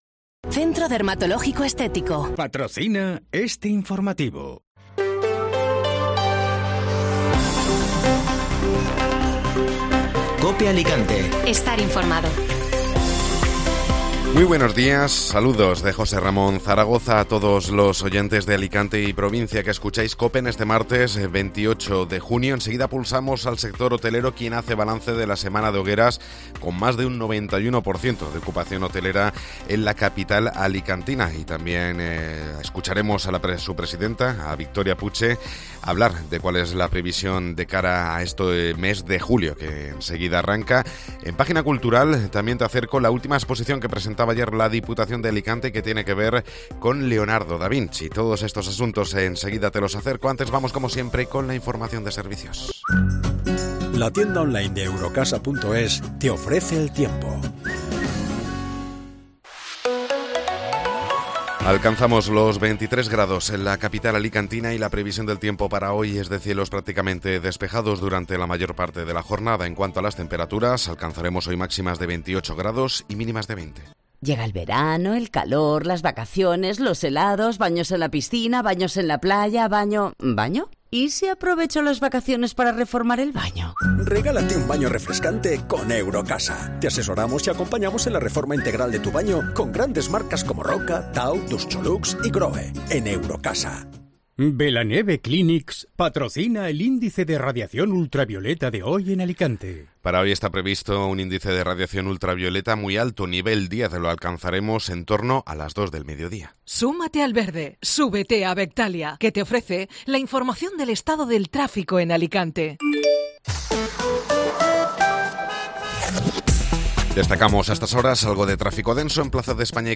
Informativo Matinal (Martes 28 de Junio)